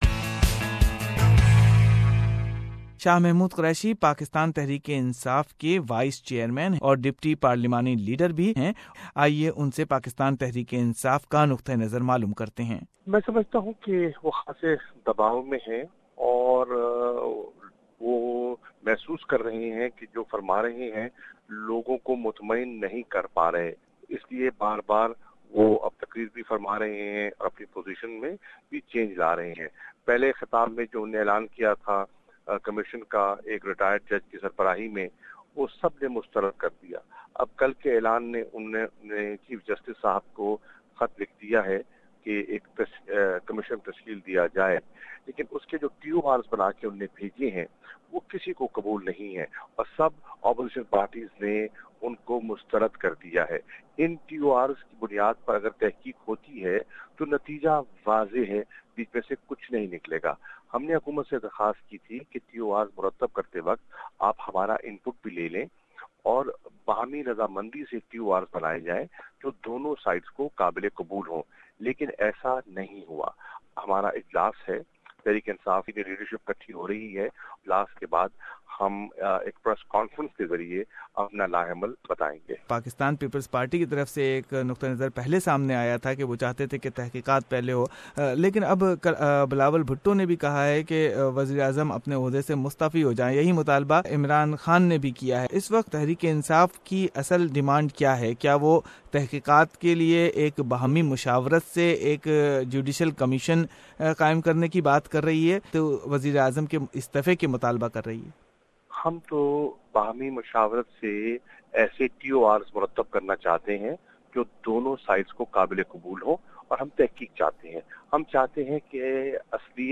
Listen talks with PTI Vice chairman Shah Mehmood Qureshi and Defence analyst Lt Gen R Talat Masood.